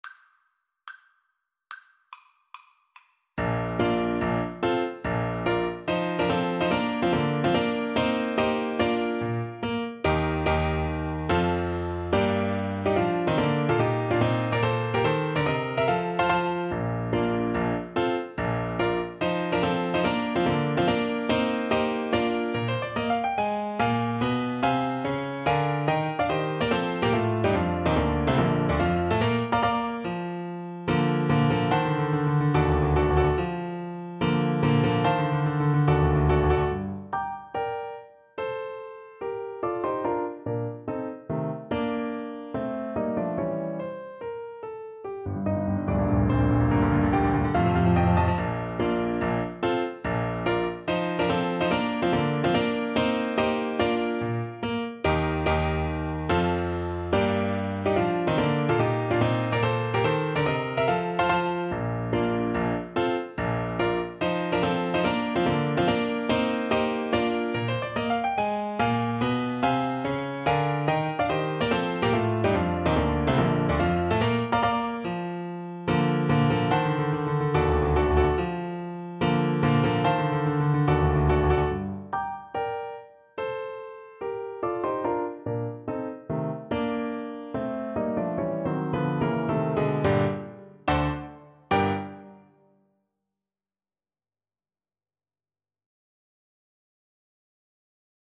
Play (or use space bar on your keyboard) Pause Music Playalong - Piano Accompaniment Playalong Band Accompaniment not yet available transpose reset tempo print settings full screen
Bb major (Sounding Pitch) (View more Bb major Music for Flute )
Allegro non troppo (=72) (View more music marked Allegro)
Classical (View more Classical Flute Music)